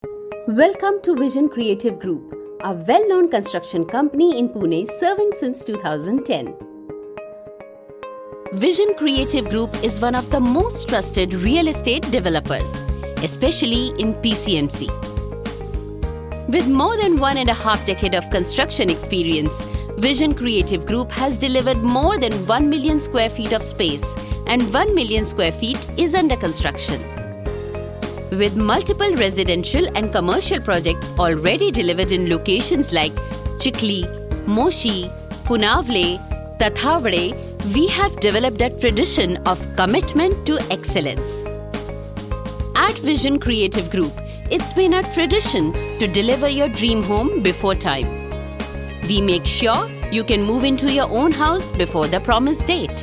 Female
My voice is pleasant , soft and compassionate.
Tour Guide